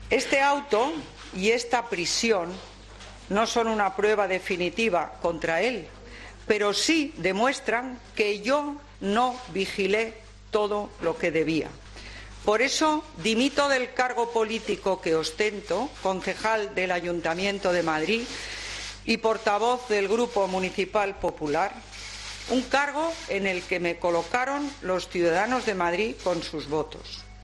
Esperanza Aguirre ha hecho estas declaraciones durante una breve comparecencia de tres minutos en la sede del grupo municipal del PP en la que, sin admitir preguntas, ha anunciado que deja su cargo de concejal y de portavoz tras la detención y el ingreso en prisión el viernes pasado del expresidente madrileño Ignacio González por el caso Lezo.
"Los ciudadanos tienen derecho a pedir que los políticos asumamos toda nuestra responsabilidad con dignidad, sin dilaciones y sin excusas y yo tengo como norma de conducta no eludir nunca mis responsabilidades y por eso presento mi dimisión", ha concluido Aguirre, visiblemente emocionada, para abandonar después la sala sin aceptar preguntas de los periodistas.